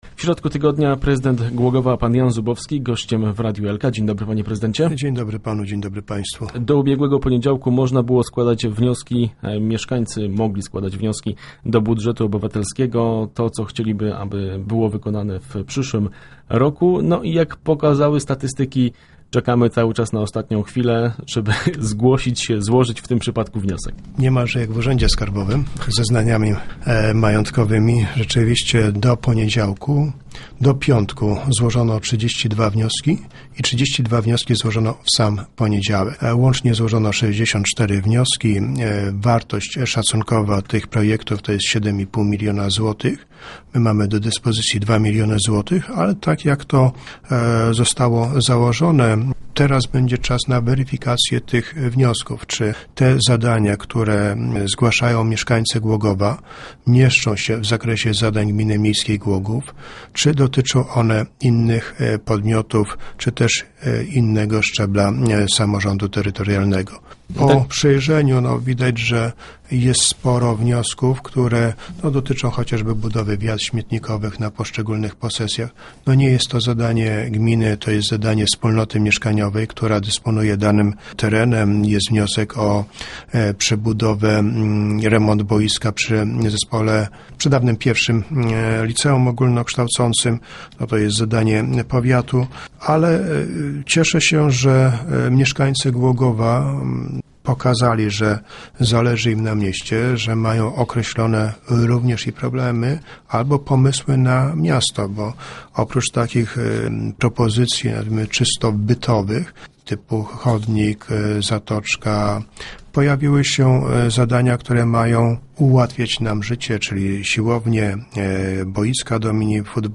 0917_re_zubowski.jpgO budżecie obywatelskim, kontrowersjach wokół odbudowy teatru, ale także o zmianach w budżecie rozmawialiśmy w radiowym studiu w prezydentem Głogowa Janem Zubowskim.